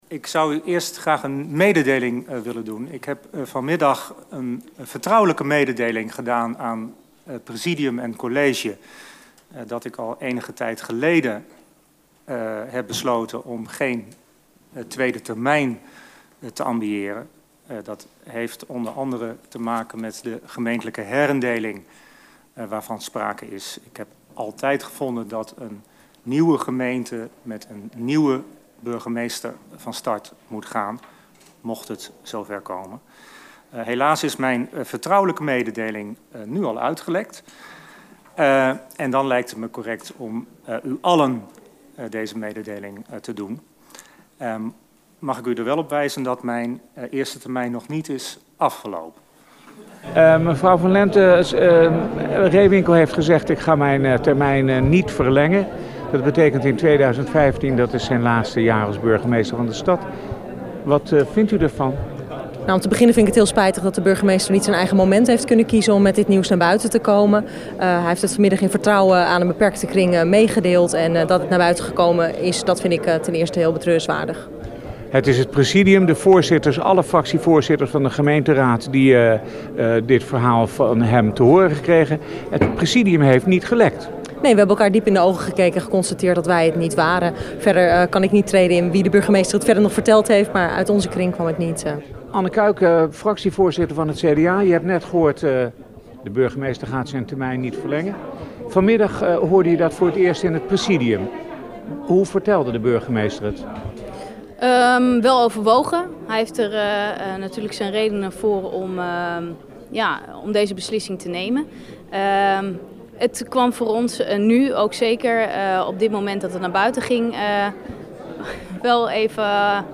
Burgemeester Peter Rehwinkel (PvdA) gaat niet voor een tweede termijn als burgervader van Groningen. Dat heeft hij woensdagavond in de gemeenteraadsvergadering bekendgemaakt.